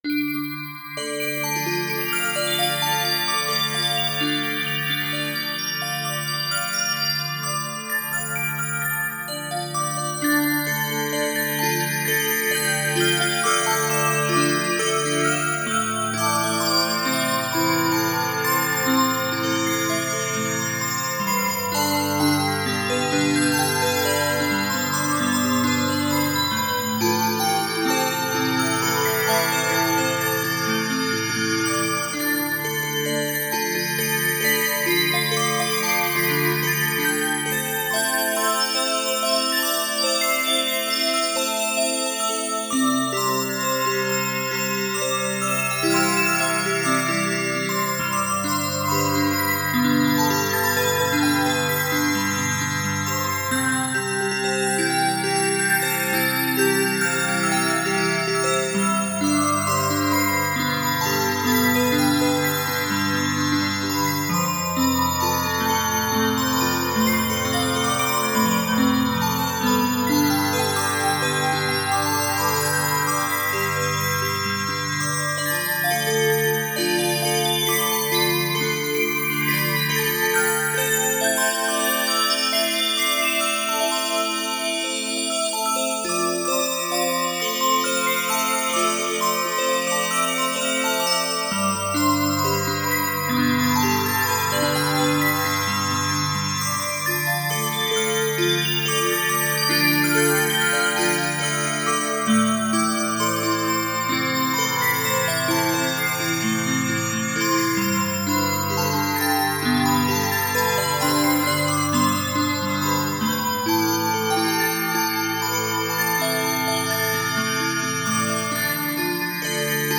Медитативная музыка Мистическая музыка Духовная музыка